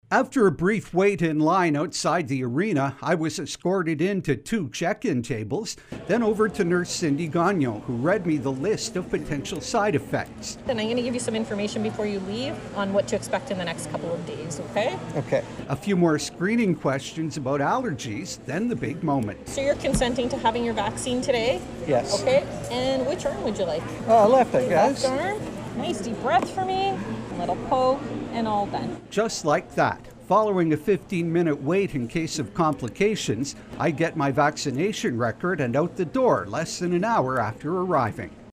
You can hear me getting my shot right here.